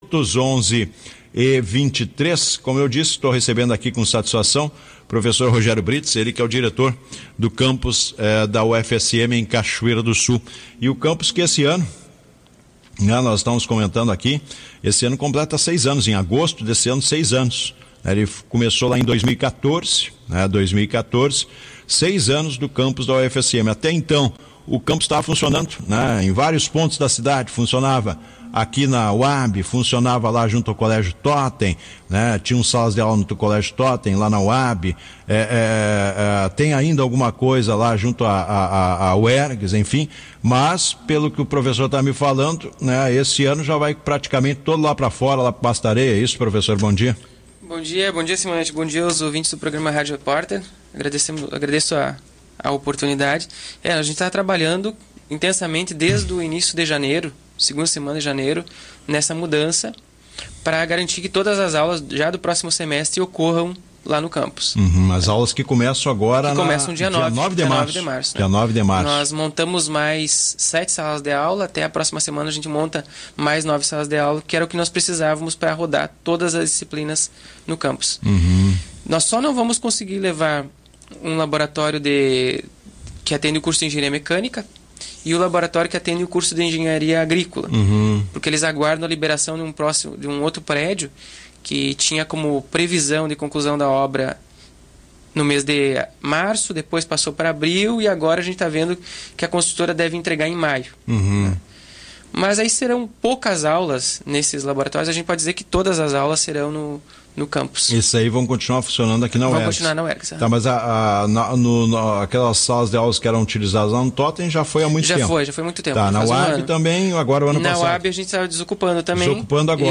Matérias veiculadas em rádios no mês de fevereiro.